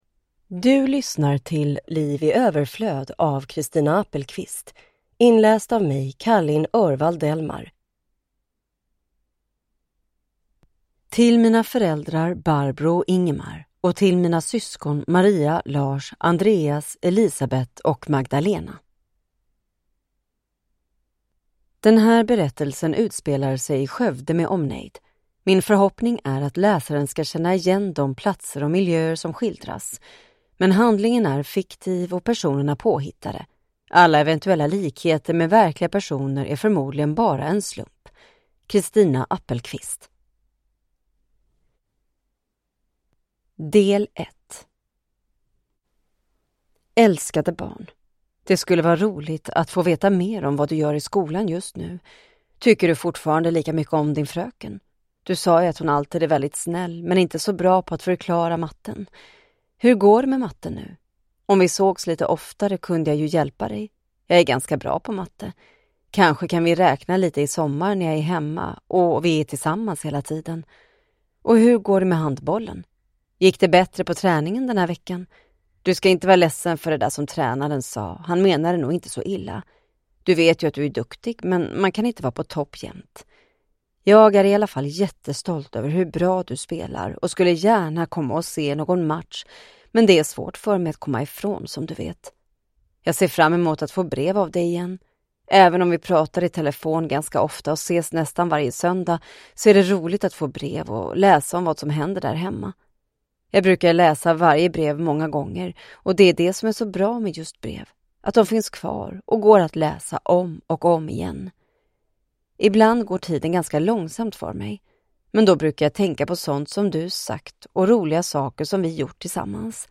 Ljudbok
Nyinspelad ljudbok med förbättrat ljud och populär inläsare!